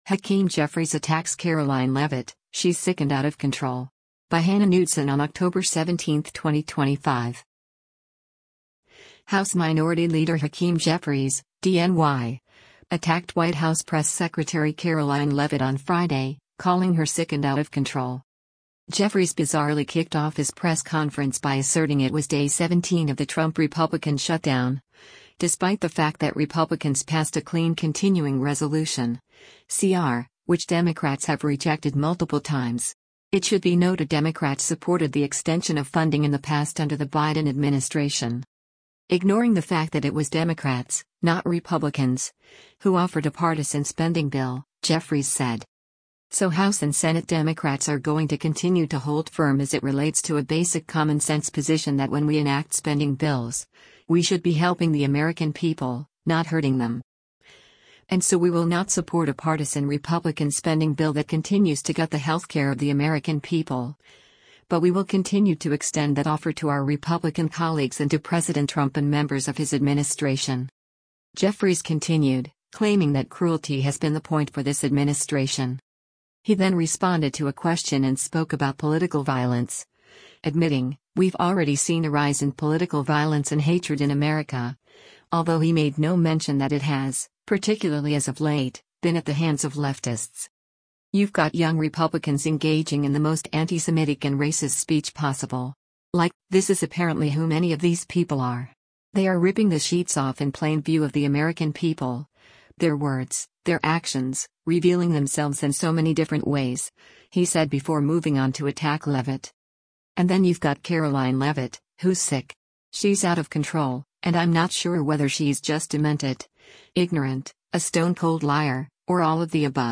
Jeffries bizarrely kicked off his press conference by asserting it was Day 17 of the “Trump-Republican shutdown,” despite the fact that Republicans passed a clean continuing resolution (CR), which Democrats have rejected multiple times.